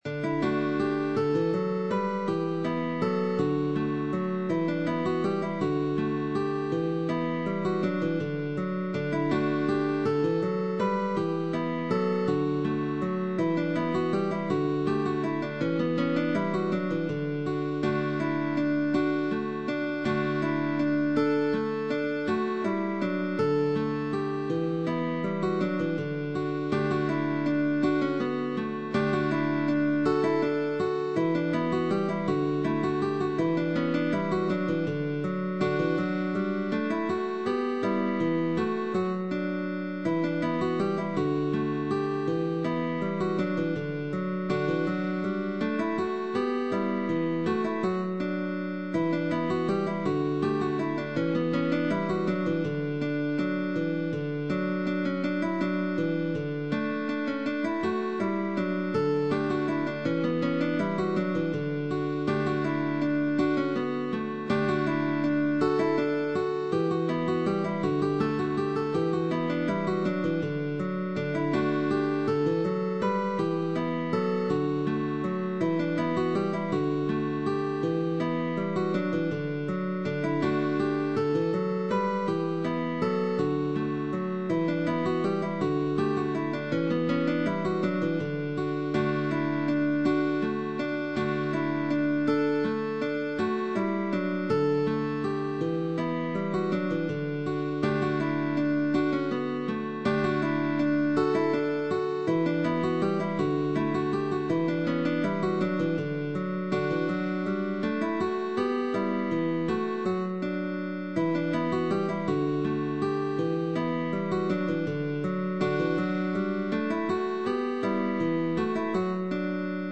GUITAR DUO
Early music